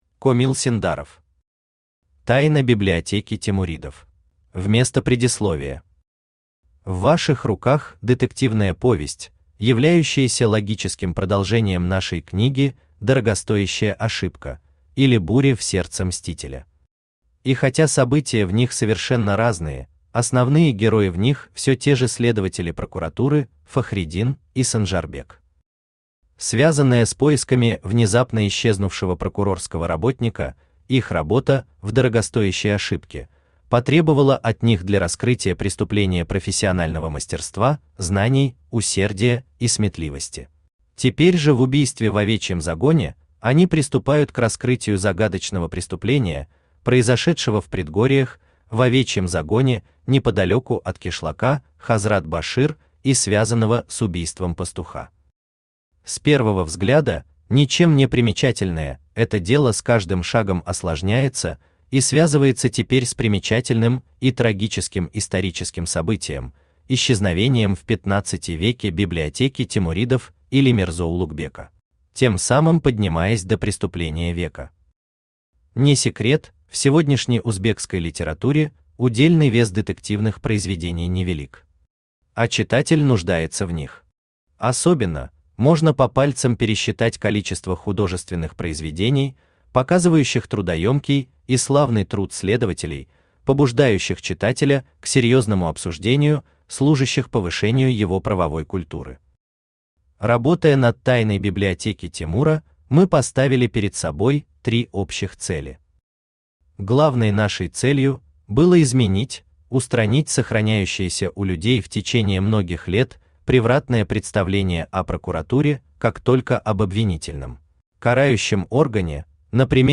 Аудиокнига Тайна библиотеки темуридов | Библиотека аудиокниг
Aудиокнига Тайна библиотеки темуридов Автор Комил Ойдинович Синдаров Читает аудиокнигу Авточтец ЛитРес.